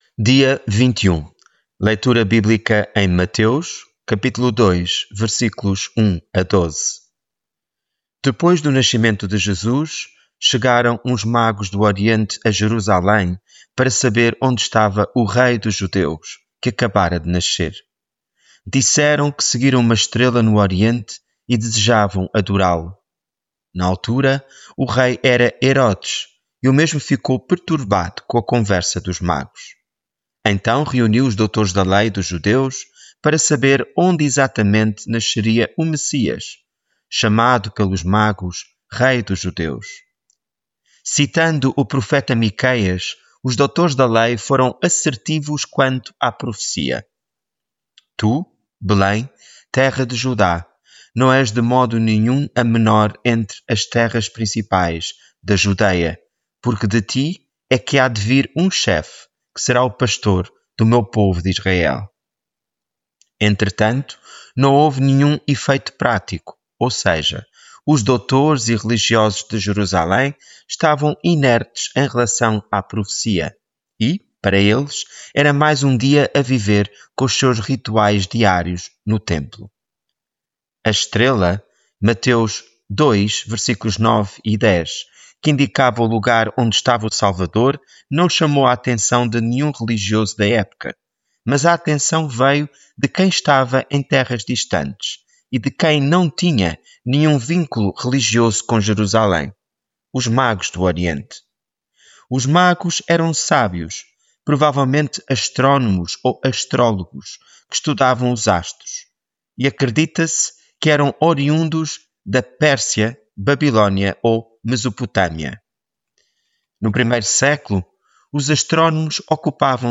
Devocional
Leitura bíblica em Mateus 2:1-12